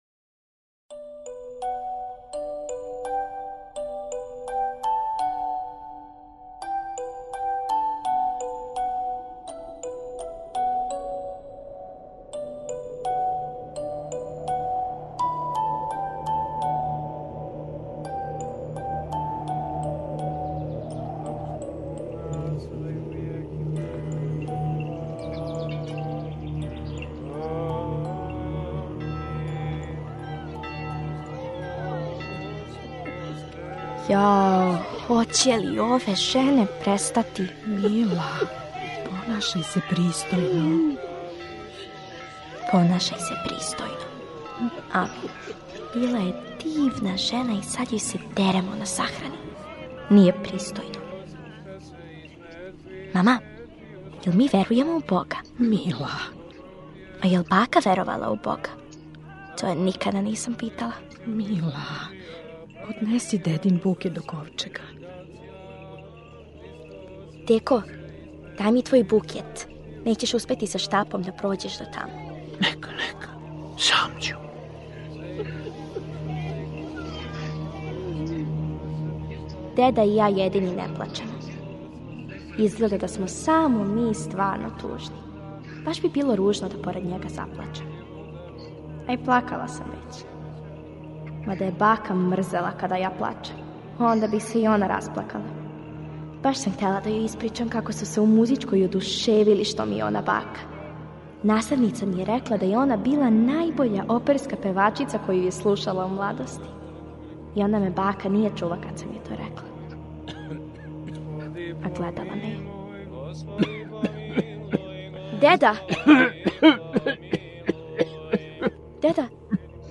Драмски програм за децу